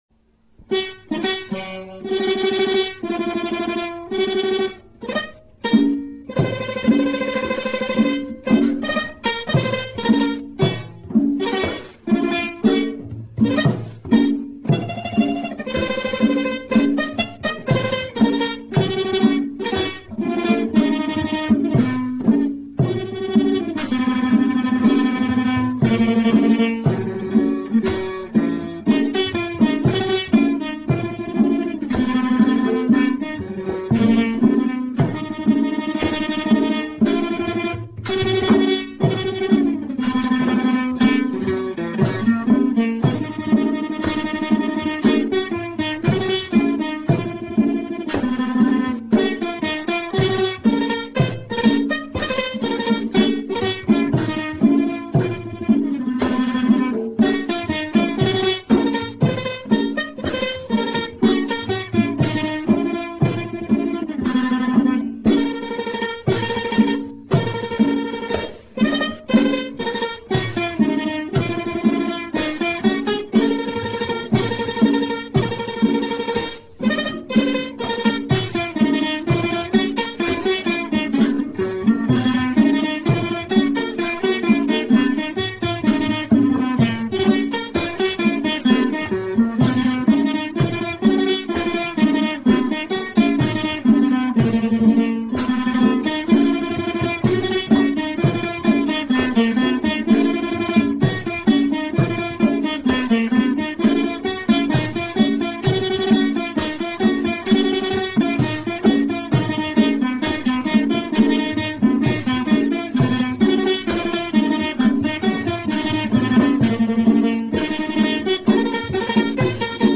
Khryang Ditt : plucked instruments
Jakhae
Today the instrument is considered distinctively Thai and one of the Thai instruments having a particularly lovely tone.